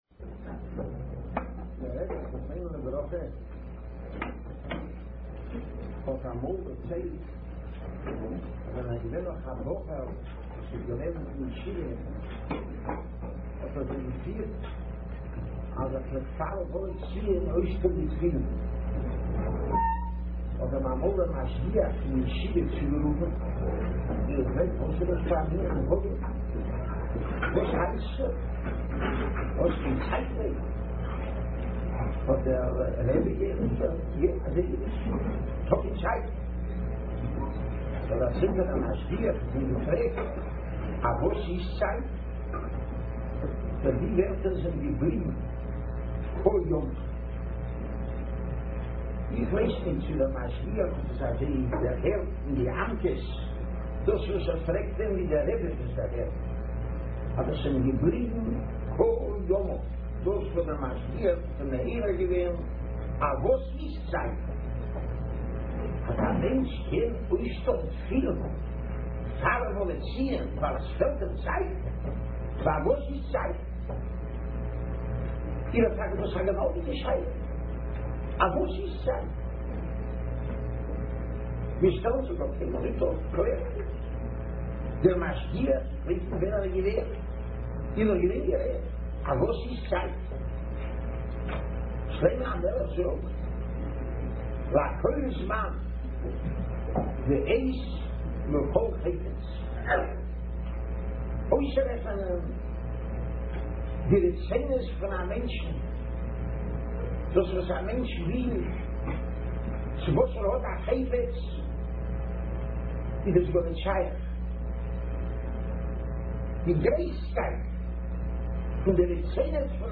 Parshas Mishpatim Shiur Daas